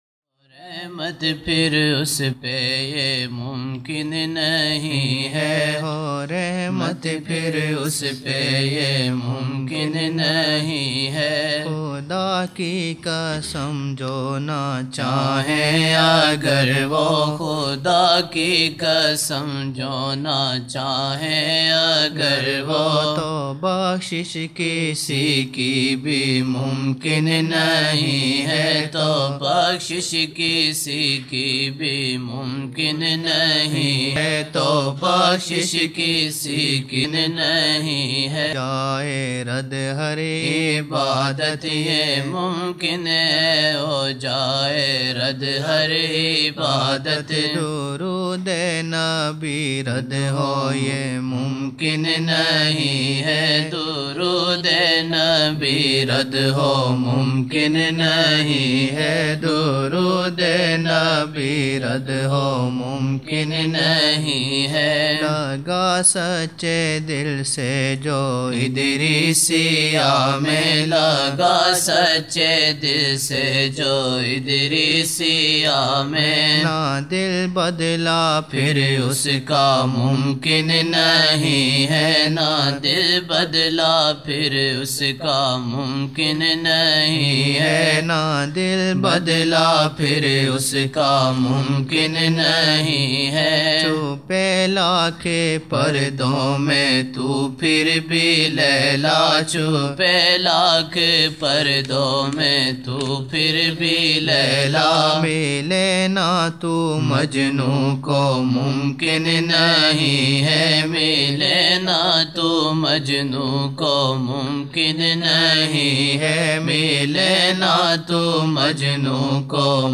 22 November 1999 - Isha mehfil (14 Shaban 1420)
عشا محفل
Naat shareef